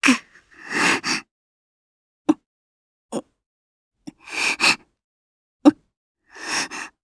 Gremory-Vox_Sad_jp_b.wav